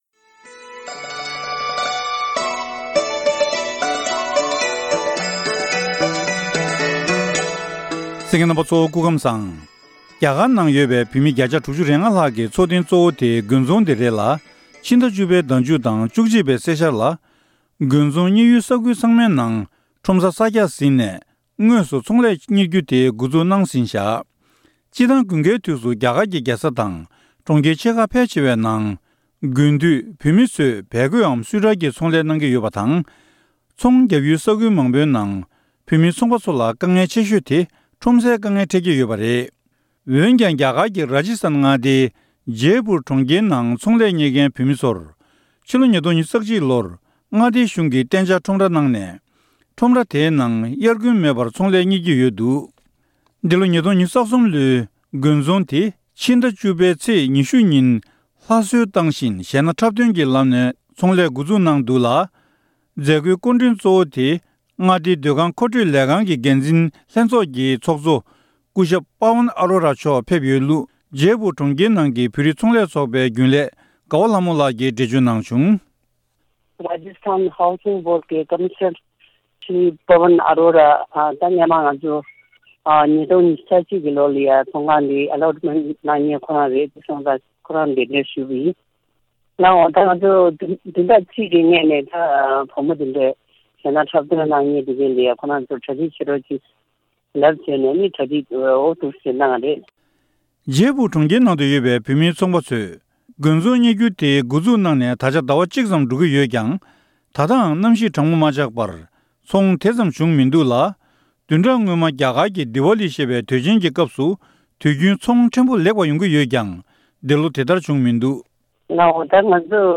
རྒྱ་གར་ནང་དགུན་ཚོང་གཉེར་ཡུལ་ས་ཁུལ་ཕལ་ཆེ་བའི་ནང་འདི་ལོ་༢༠༢༣ལོའི་ཚོང་ལས་འགོ་འཛུགས་ཟིན་འདུག་ཀྱང་ད་ལྟ་ཚོང་དེ་ཙམ་མེད་ལུགས་ཚོང་པ་ཁག་གི་ངོ་སྤྲོད་གནང་བ།